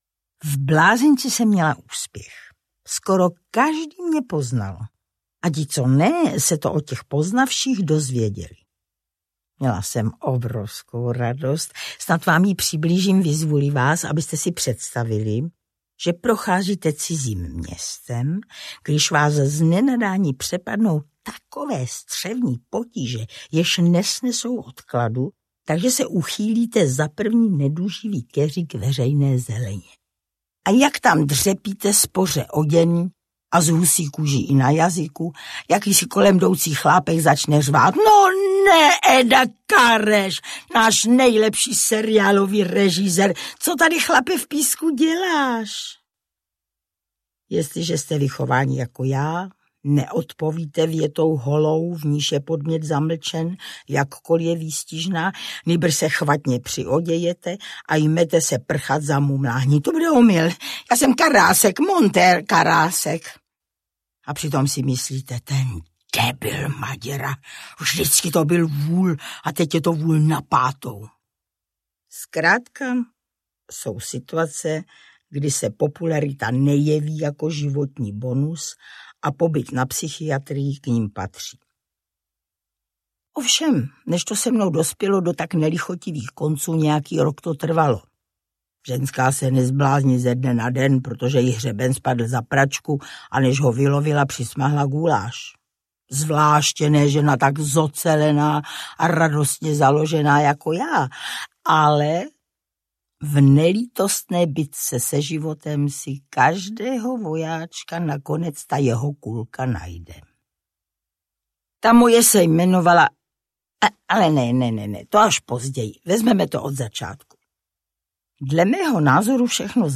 Jak jsem se zbláznila audiokniha
Ukázka z knihy
• InterpretIvanka Devátá